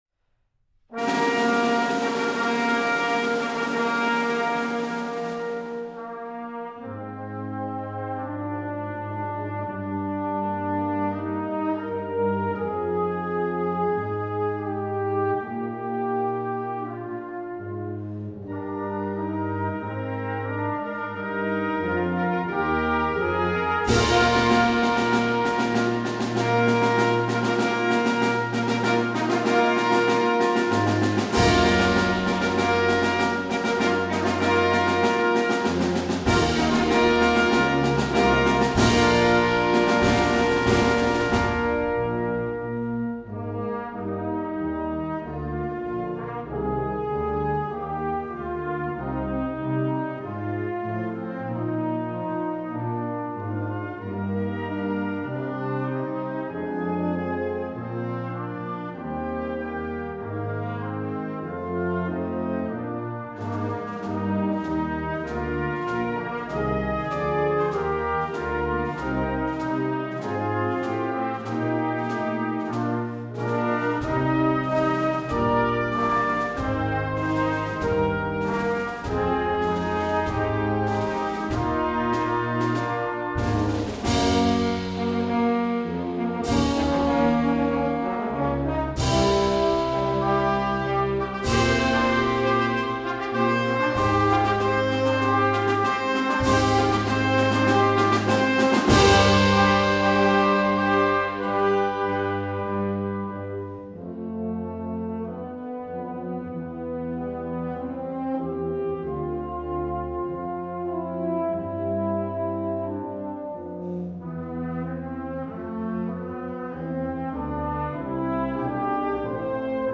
Partitions pour ensemble flexible, 5-voix + percussion.